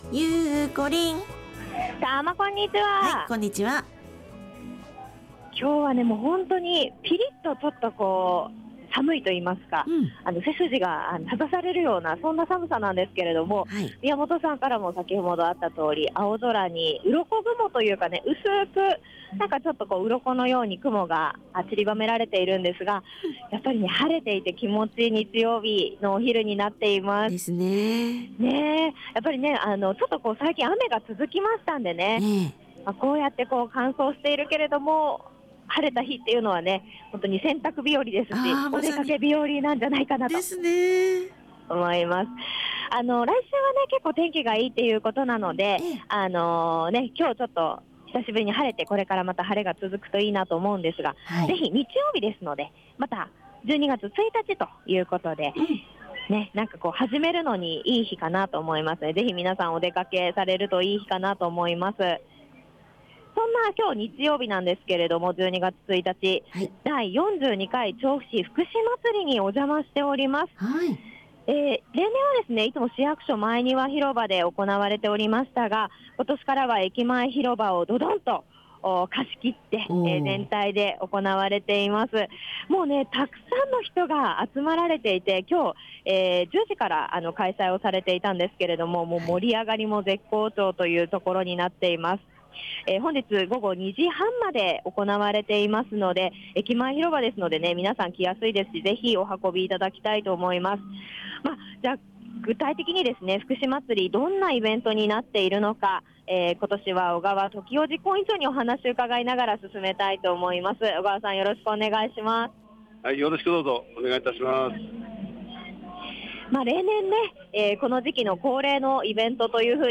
★たまにち 街角レポート
令和元年もあともう一ヶ月となった12月1日の日曜日、お邪魔したのは第42回調布市福祉まつり！